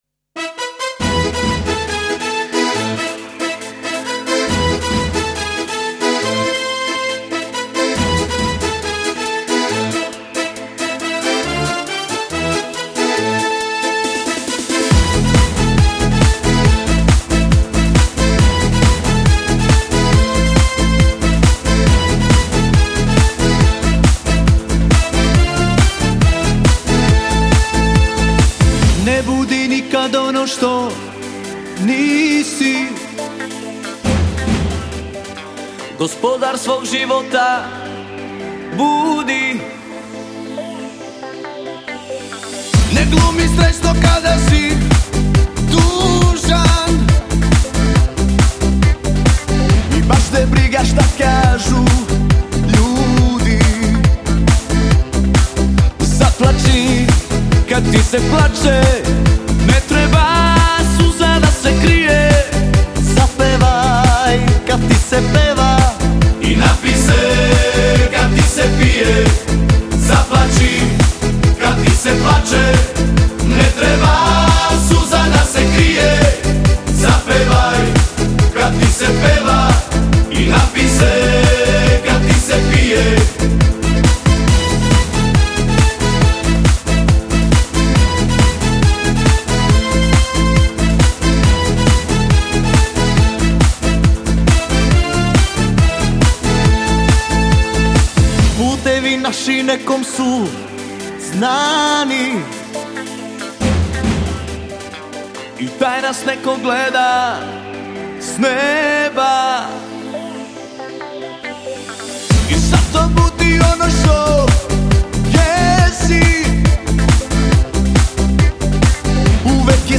Для любителей современной сербской эстрадной музыки.